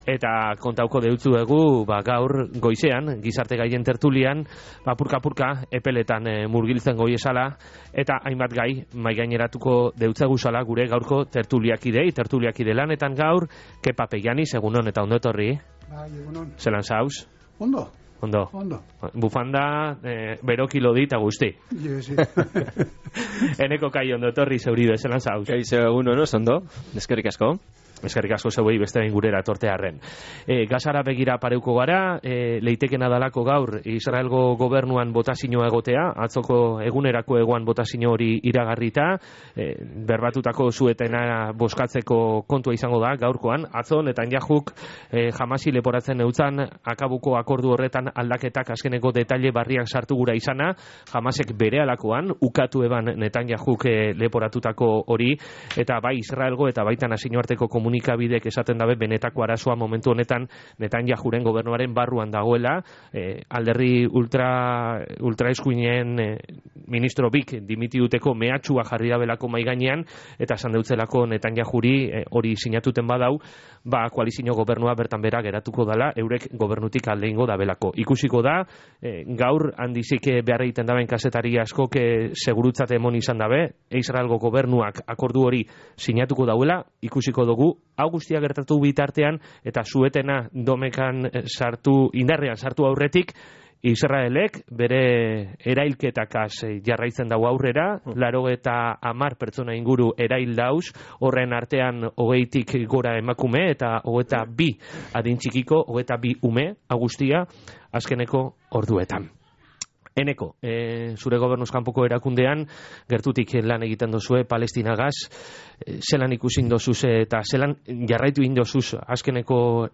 Gizarte gaien tertulian